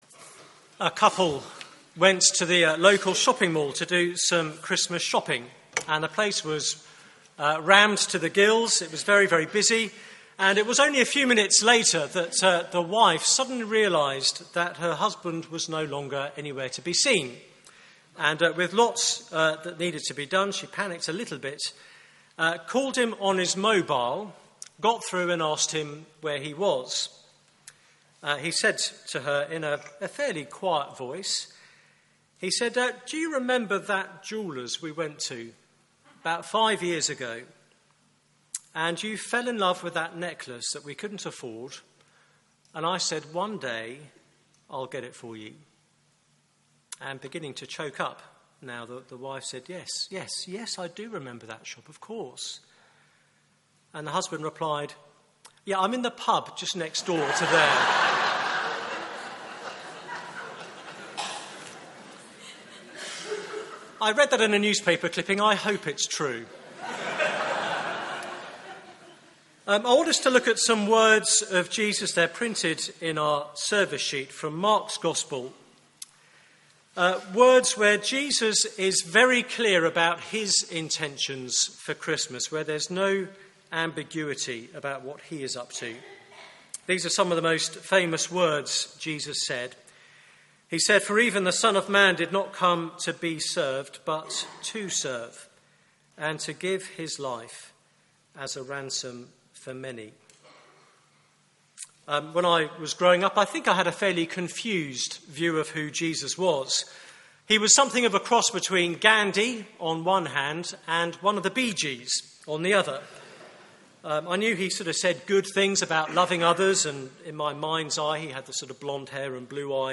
Service Type: Weekly Service at 4pm